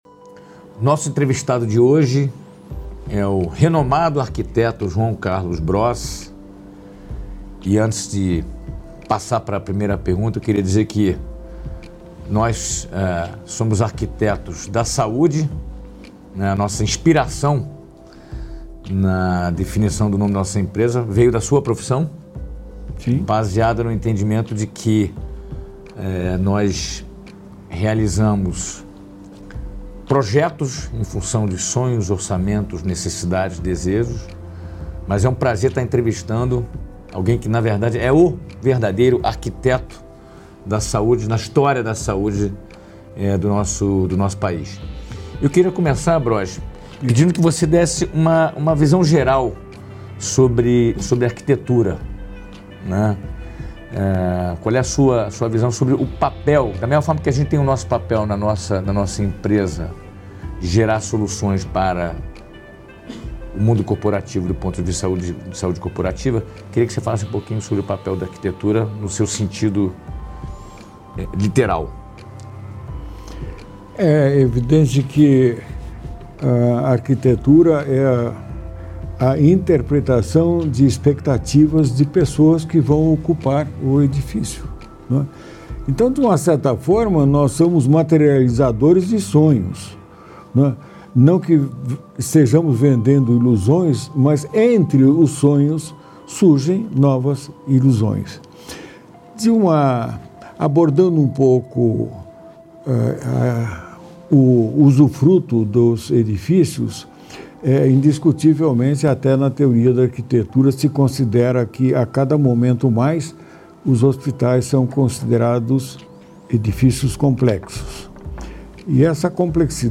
Uma entrevista com o arquiteto de referência no desenvolvimento de projetos arquitetônicos para hospitais